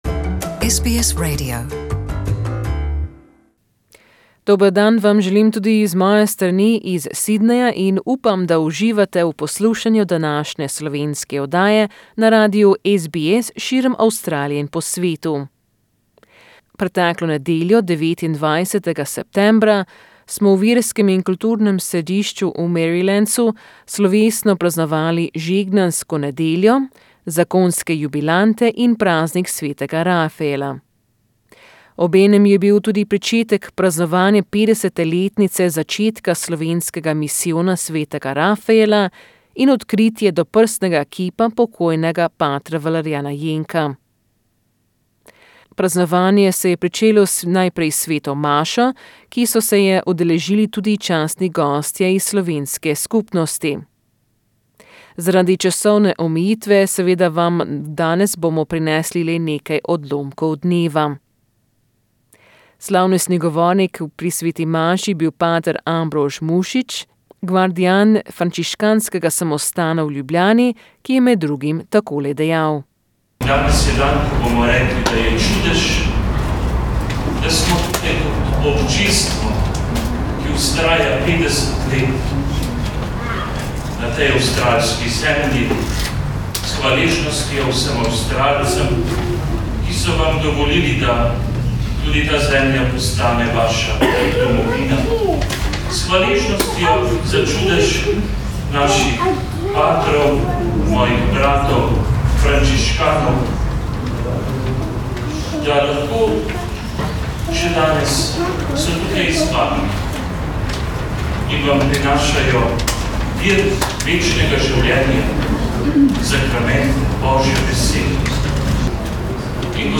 This feature gives you a flavour of the day's proceedings.